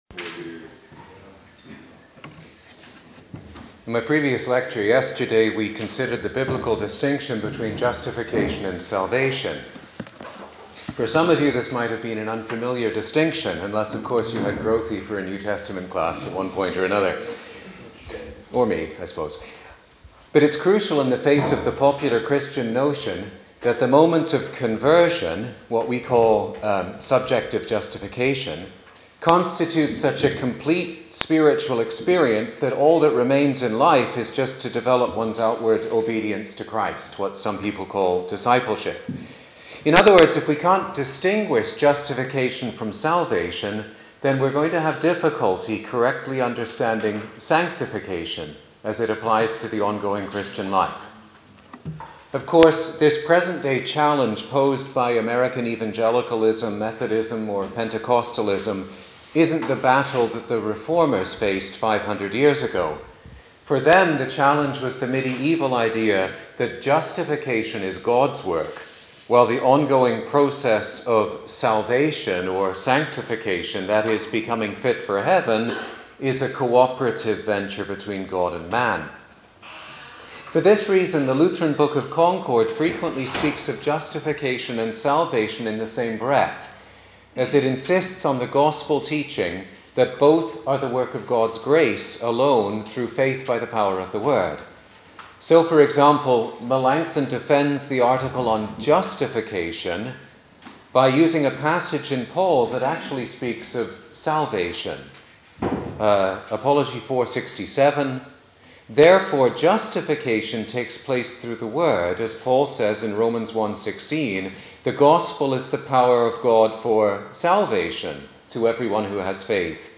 The present recording (in English) comes from a repeat of the lecture at the LCC East District Pastors' Conference,…